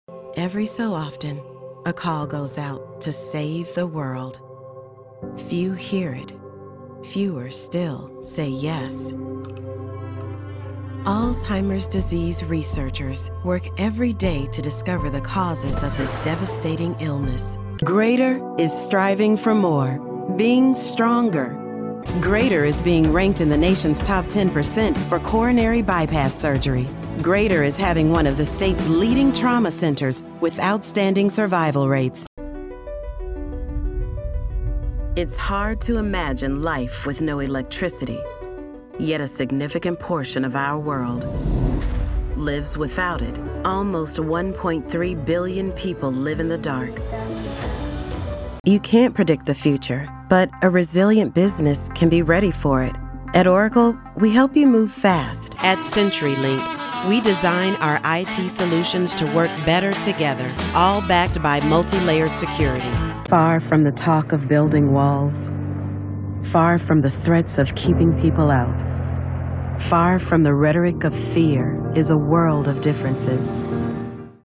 Inglés (Americano)
Llamativo, Seguro, Natural, Suave, Empresarial
Corporativo
She works from an amazing home studio with professional equipment.